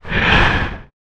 exhale.wav